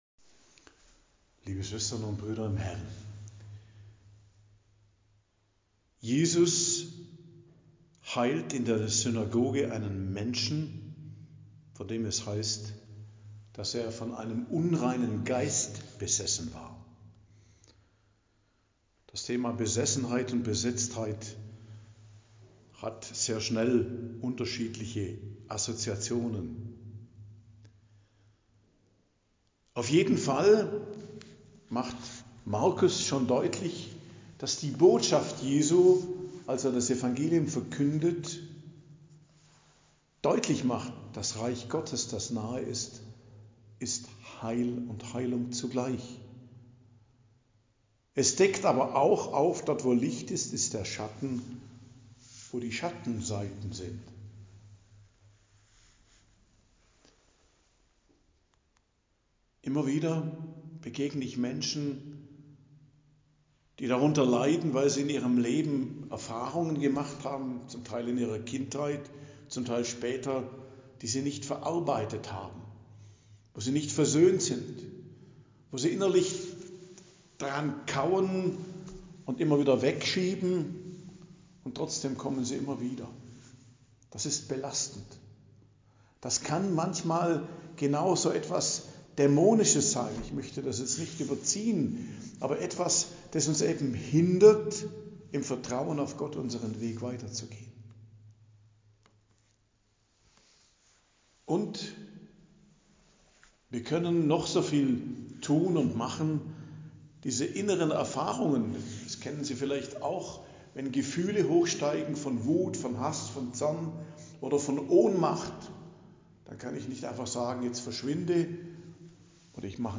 Predigt am Dienstag der 1. Woche i.J. 14.01.2025